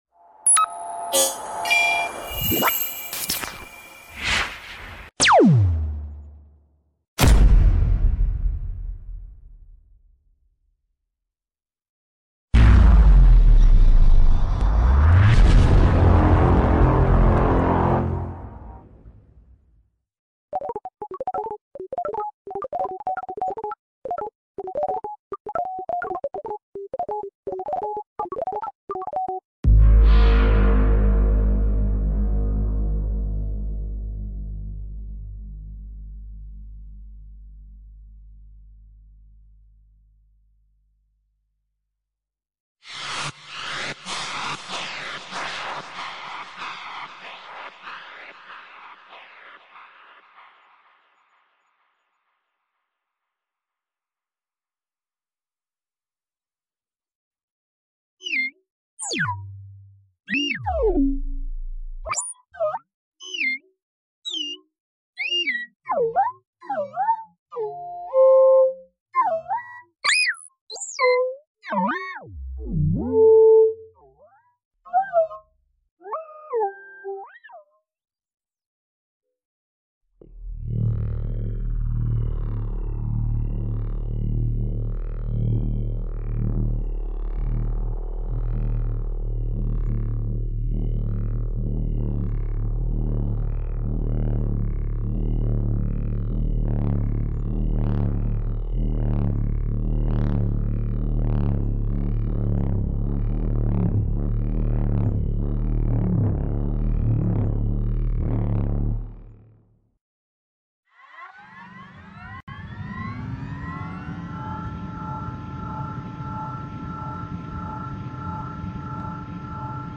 Royalty Free Sci Fi Sounds.
Perfect Mp3 Sound Effect Royalty Free Sci-Fi Sounds.